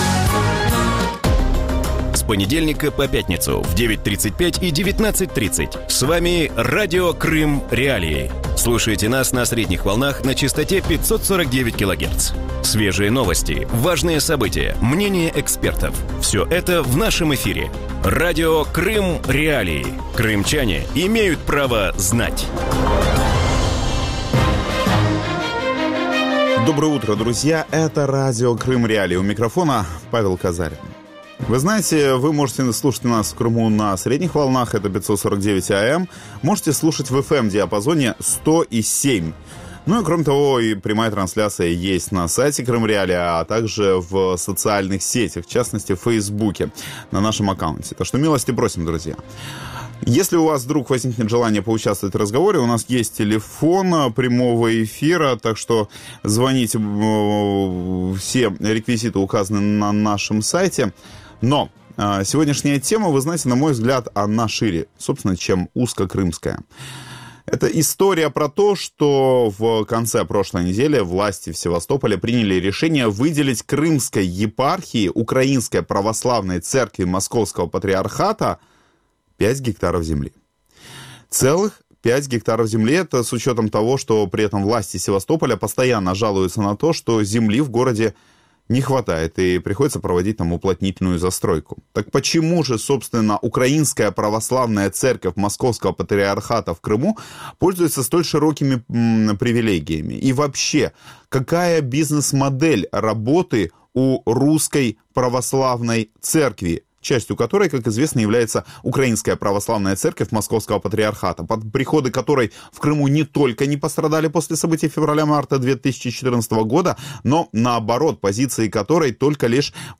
Утром в эфире Радио Крым.Реалии говорят о недвижимом имуществе и земельных участках для церкви.